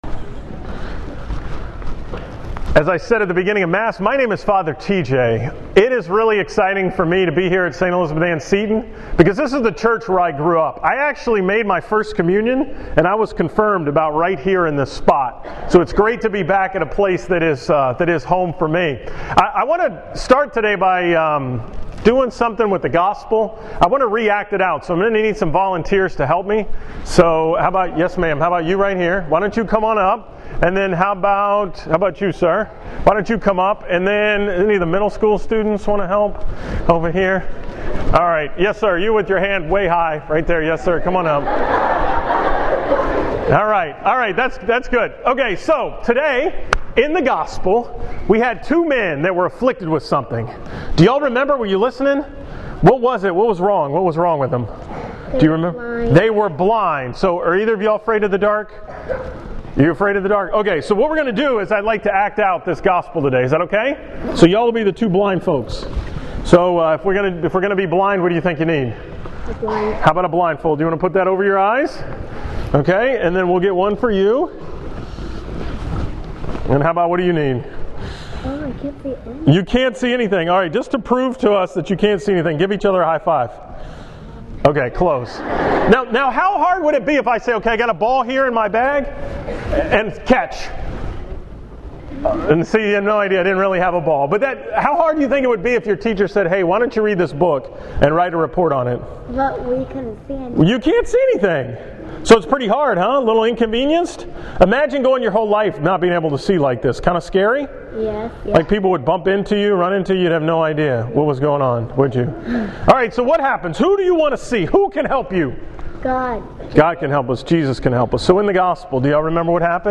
From the school Mass at St. Elizabeth Ann Seton on Friday, December 4, 2015